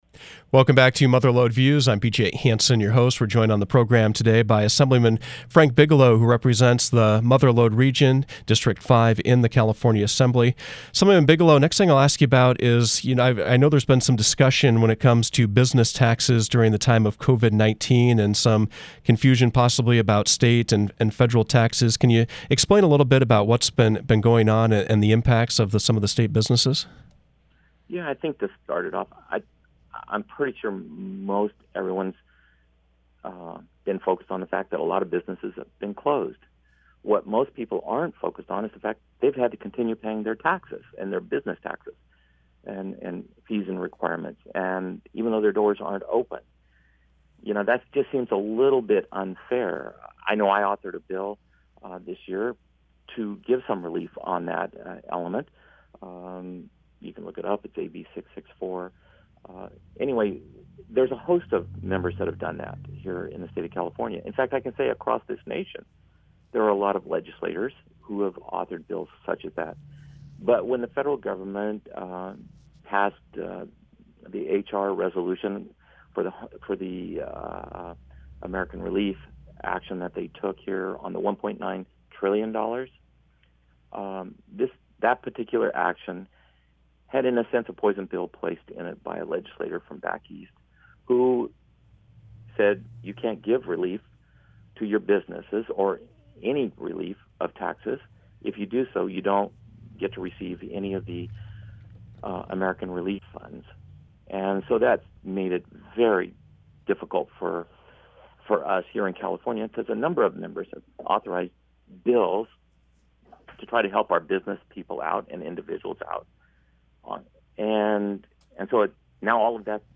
Mother Lode Views featured District Five Republican Assemblyman Frank Bigelow. He gave his opinion about the state’s response to the COVID-19 pandemic, Governor Newsom’s new budget, wildfire risk, efforts to curb the loss of fire insurance, and legislation up for a vote at the state capitol.